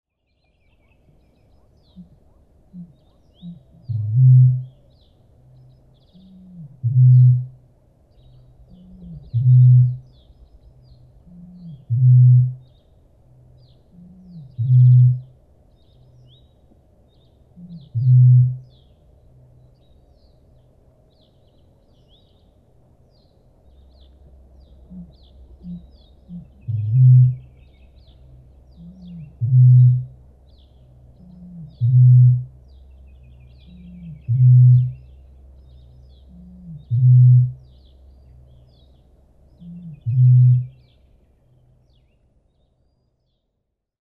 Kaulushaikara
Tunnusomainen ääni.
Kaulushaikaran ääni – kuin pulloon puhallus – kantaa hyvin pitkiä matkoja, jopa useita kilometrejä.